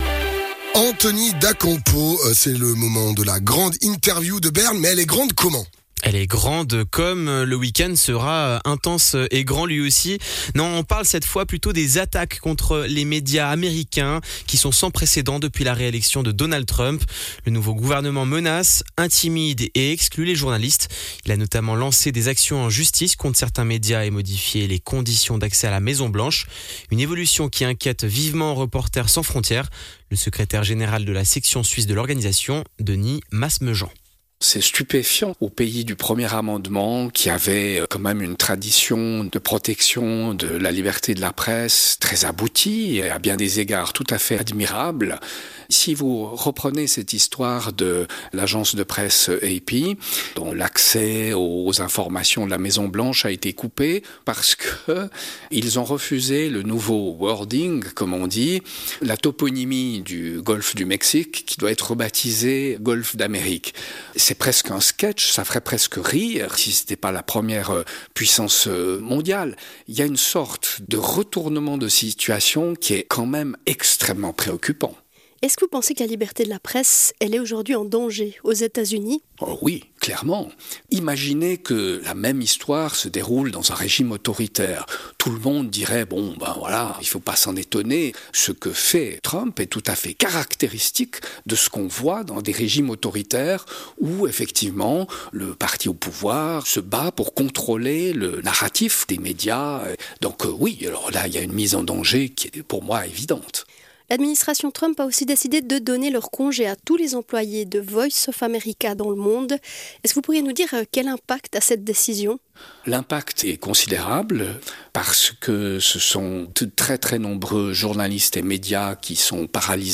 Grande Interview de nos correspondants à Berne